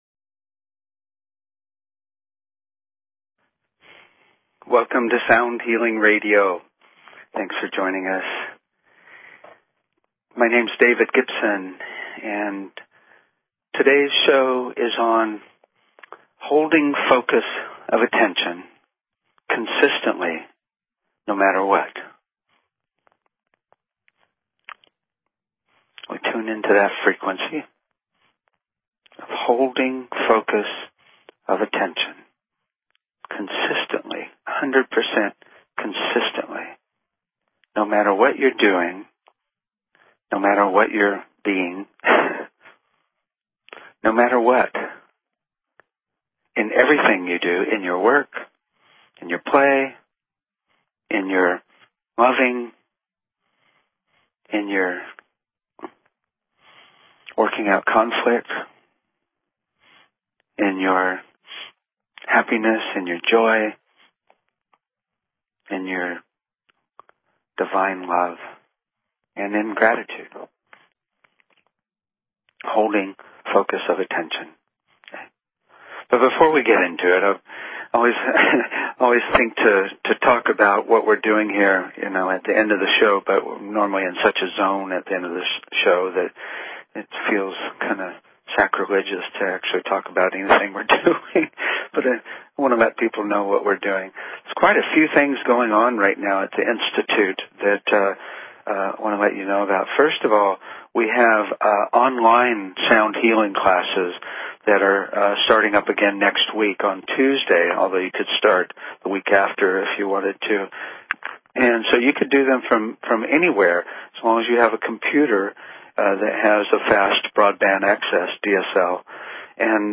Talk Show Episode, Audio Podcast, Sound_Healing and Courtesy of BBS Radio on , show guests , about , categorized as
We will explore the use of intention and "riding the sound wave" of the focus in order to sustain the focus. We will also listen to a musical meditation for Holding Focus of Attention for Everyone on the Planet (that is doing good) --Therefore, helping to resonate a consistent vibration.